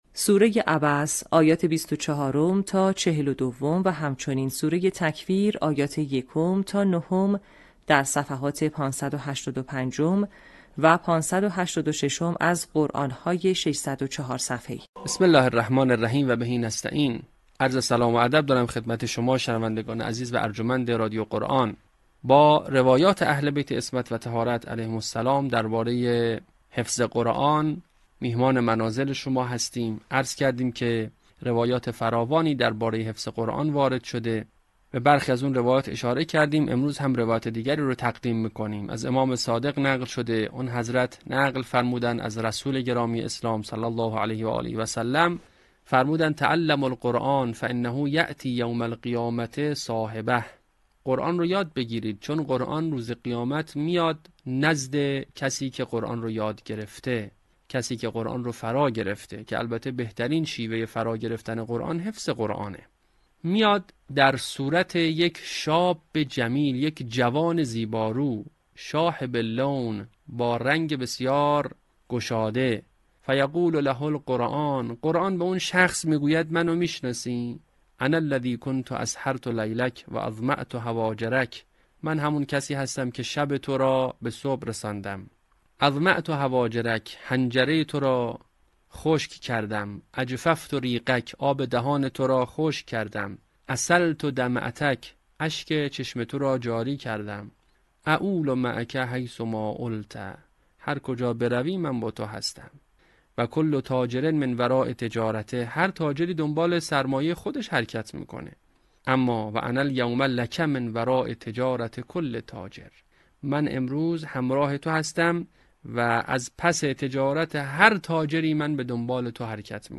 صوت | آموزش حفظ جزء ۳۰، آیات ۱ تا ۹ سوره تکویر